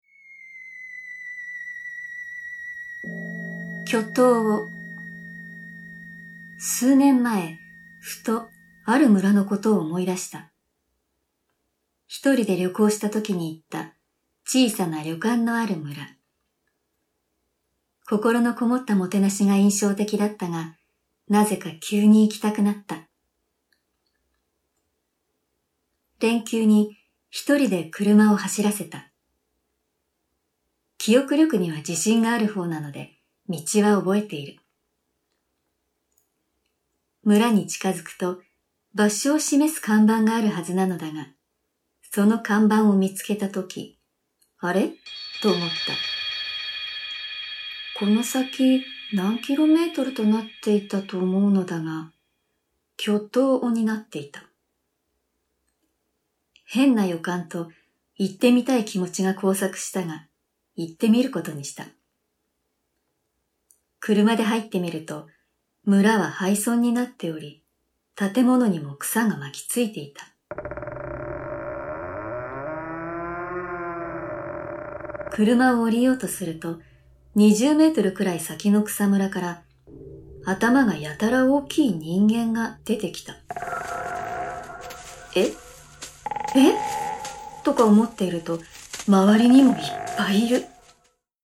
SEにもこだわり、最先端技術を駆使し、擬似的に3D音響空間を再現、格別の臨場感を体感出来ます！